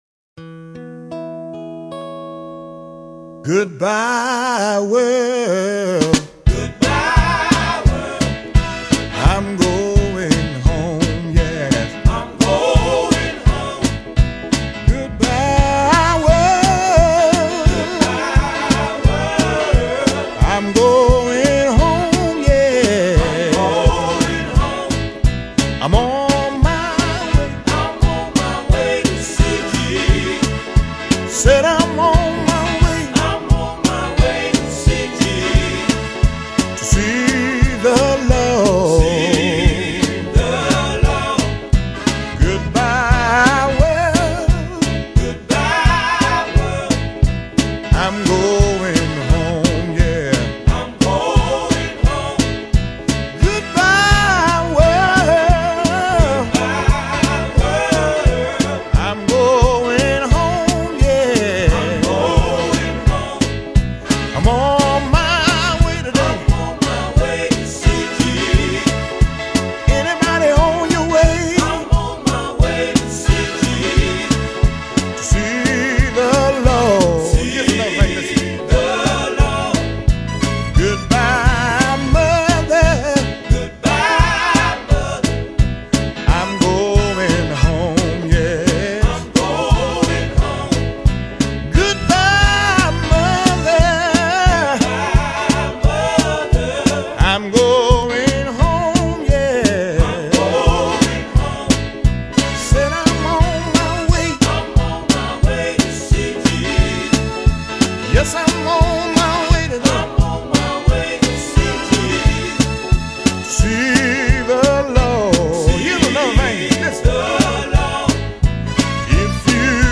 When it comes to traditional gospel quartet recordings
gospel quartet music